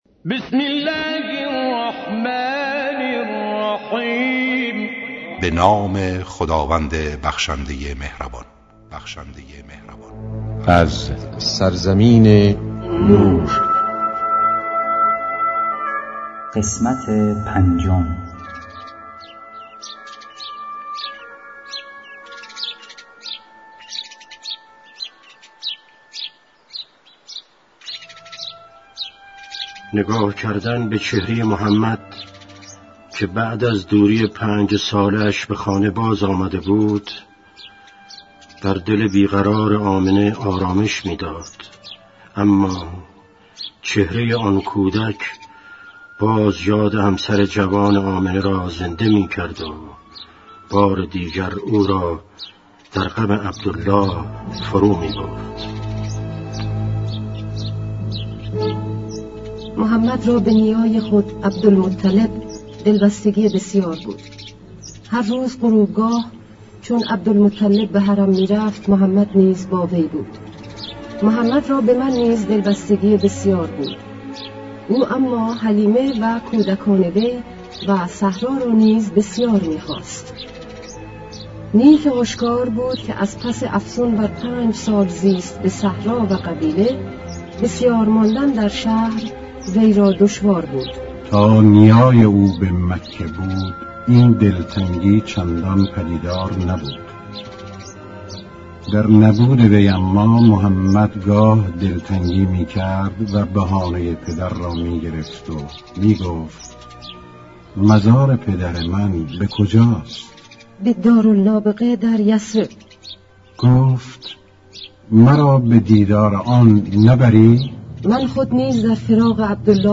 با اجرای مشهورترین صداپیشگان، با اصلاح و صداگذاری جدید
کتاب گویا